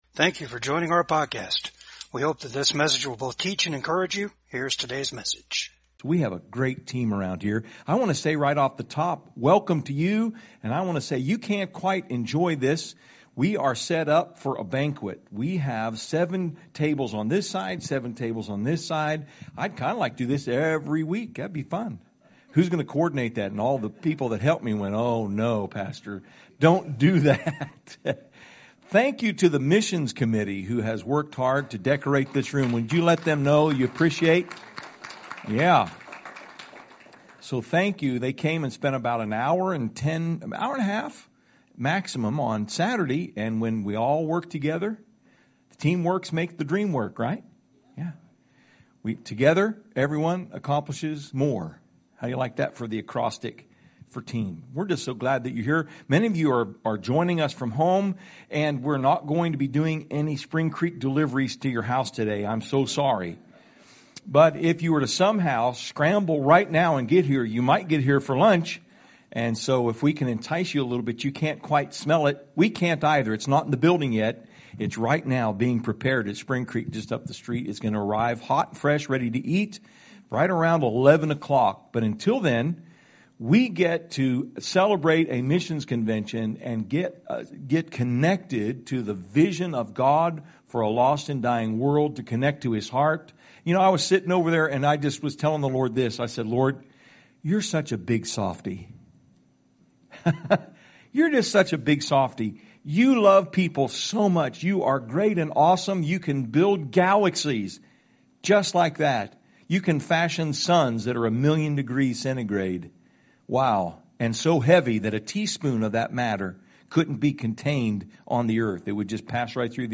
2020 MISSIONS CONVENTION – COMPELLED PT. 1